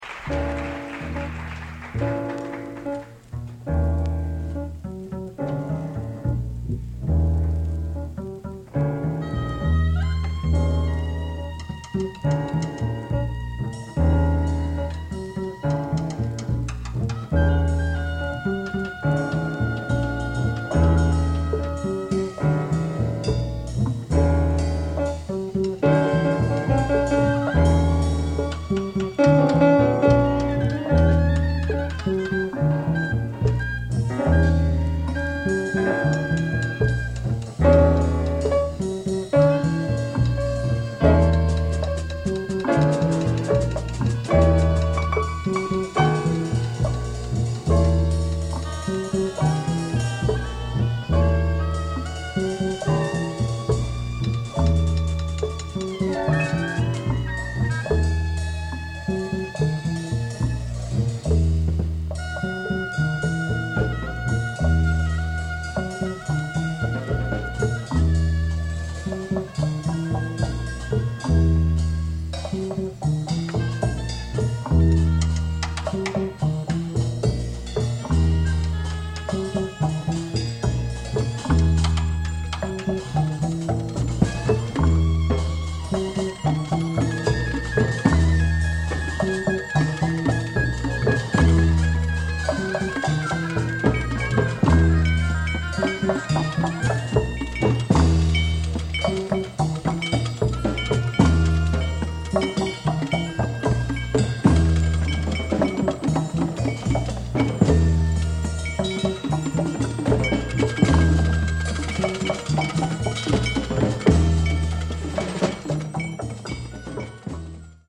Recorded May, 1966 during tour of N.Y. state colleges.